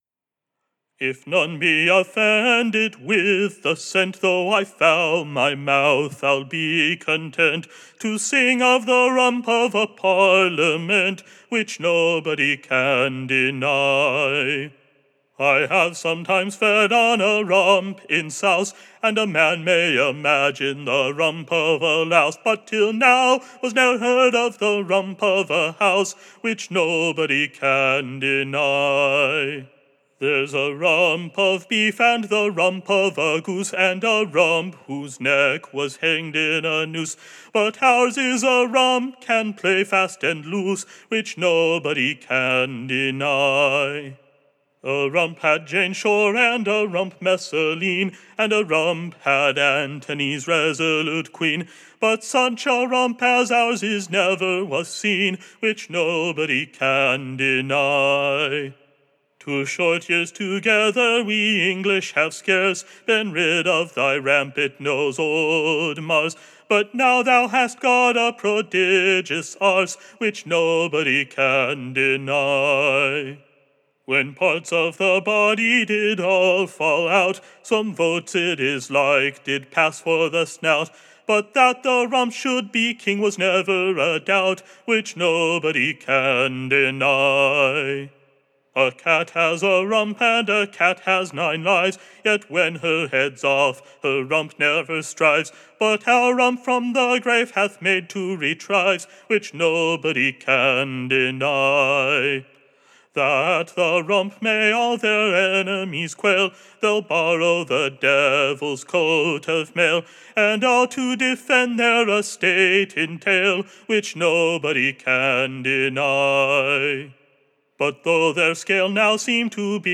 “The Re-Resurrection of the Rump,” 1659, Bridgewater Collection, Huntington HEH 133299, EBBA 32128; sung to the shortened and Dorian version of “Greensleeves” (sung in major), titled on the ballad sheet after a tradesman ballad: “To the Tune of the Blacksmith.”